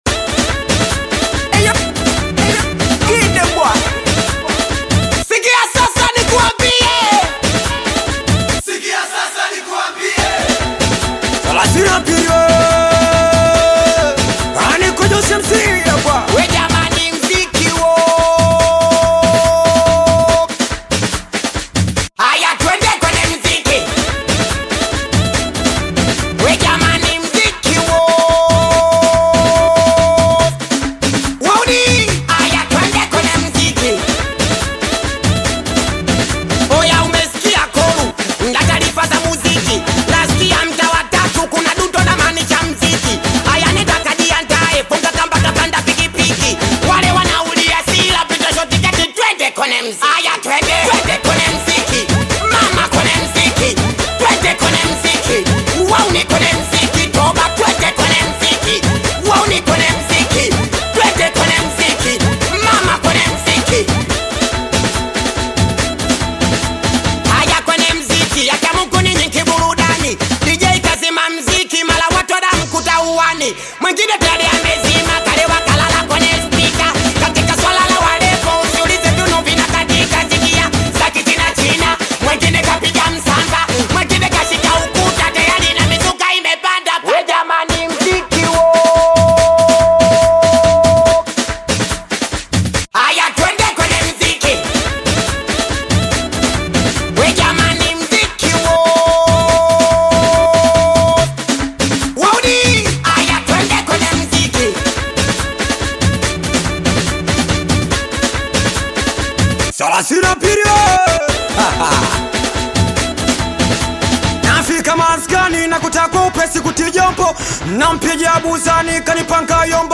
Singeli music track
Bongo Flava